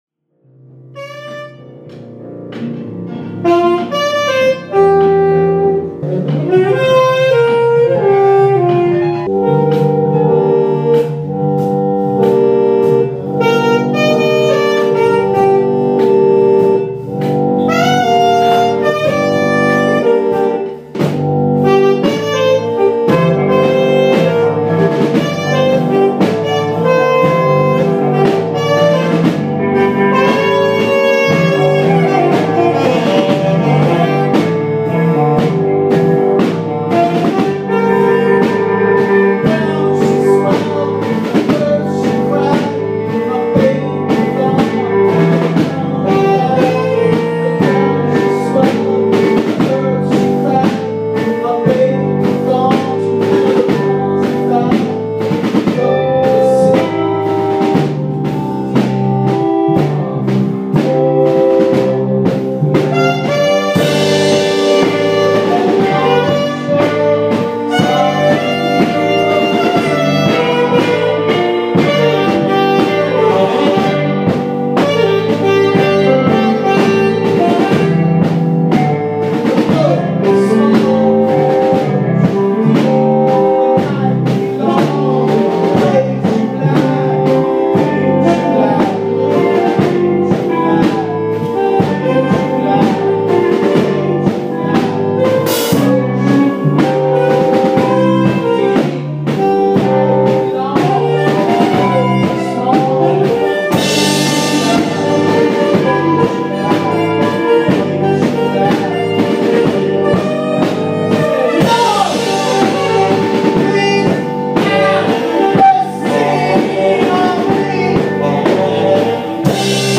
sax
keyboard/moog
drums
bass/voice
vocal/guitar